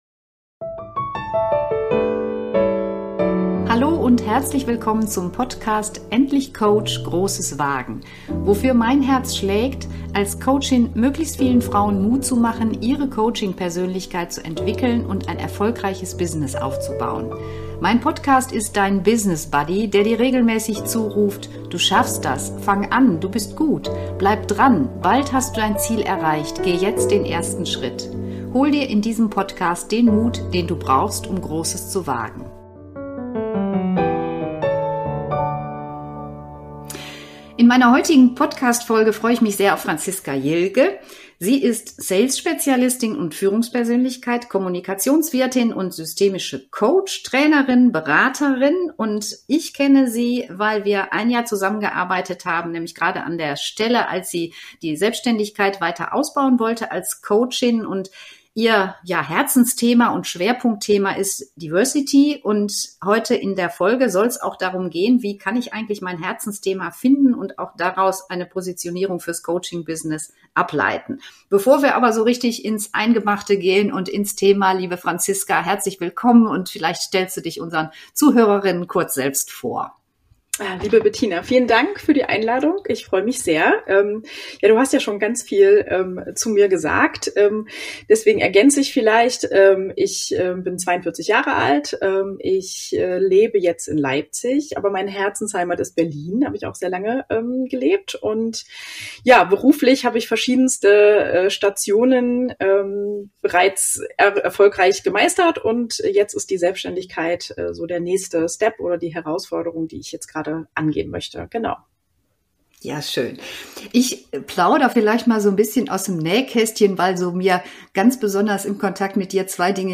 Wie finde ich mein Herzensthema? Interview